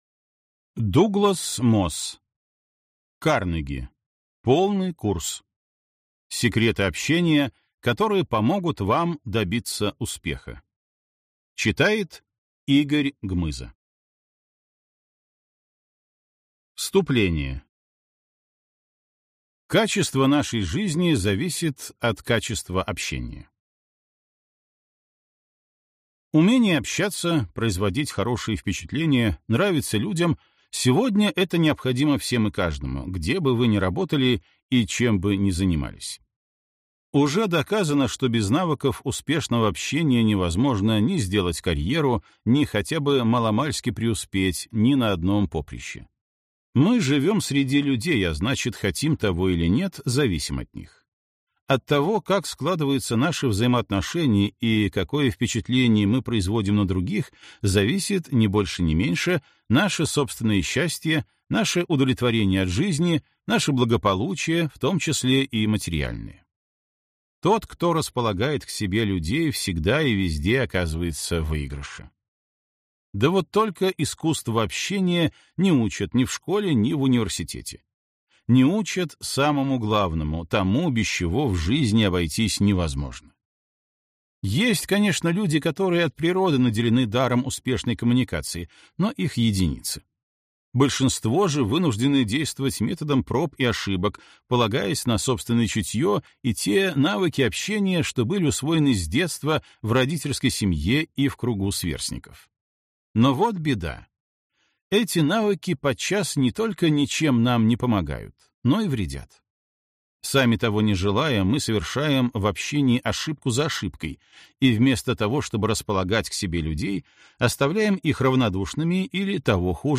Аудиокнига Карнеги. Полный курс. Секреты общения, которые помогут вам добиться успеха | Библиотека аудиокниг